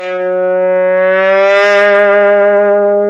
Из какого фильма этот аудиофрагмент?